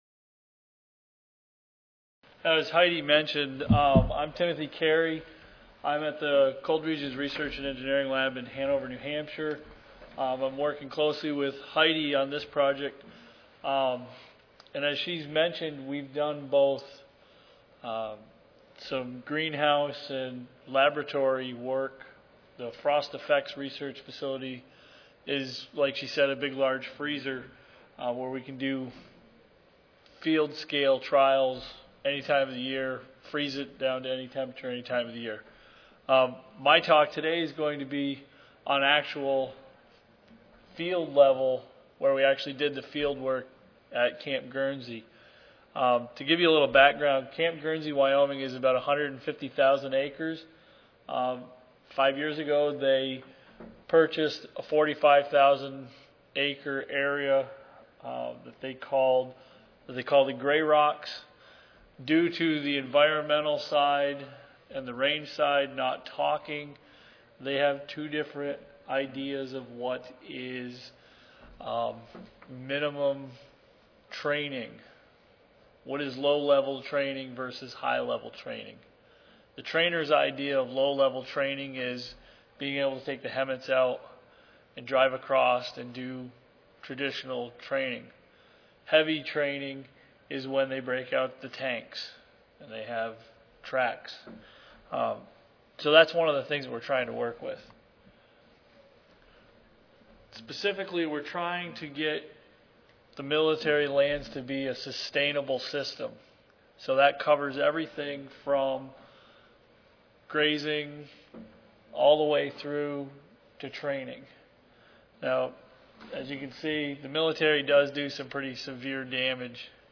Camp Guernsey Wyoming Recorded Presentation Audio File